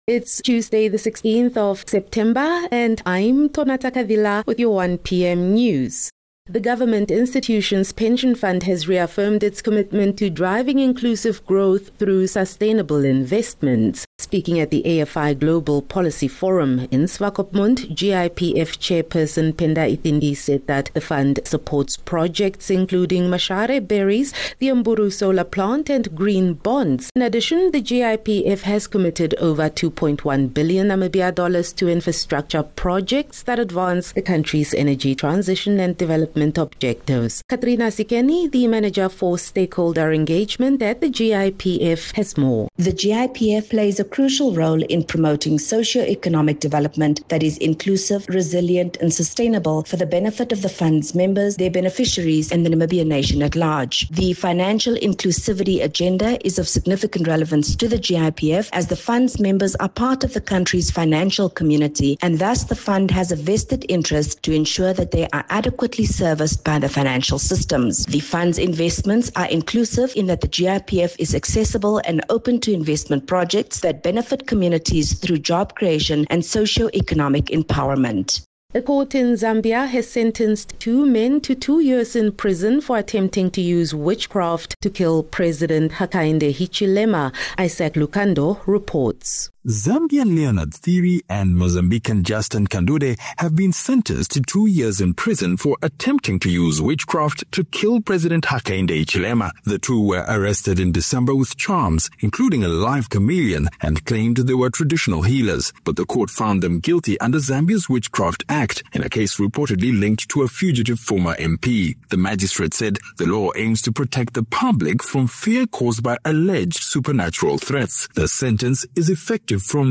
16 Sep 16 September - 1 pm news